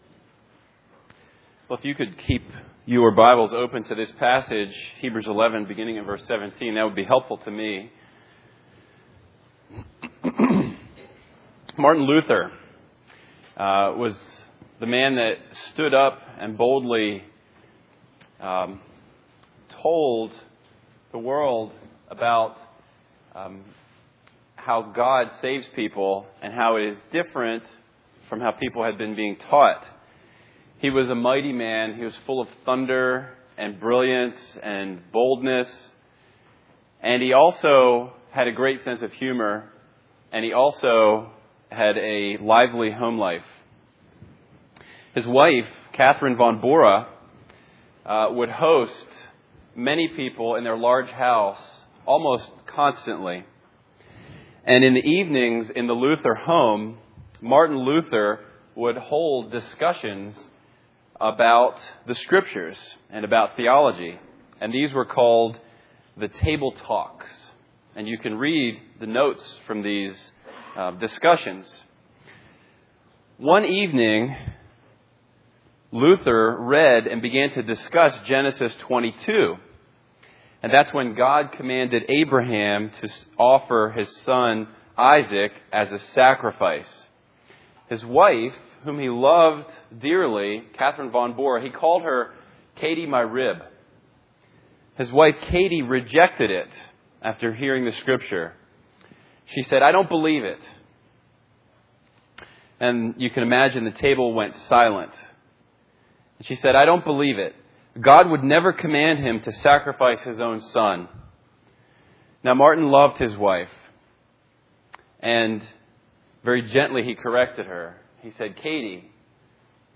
A Collection of 2016 Sermons from Windsor Baptist Chruch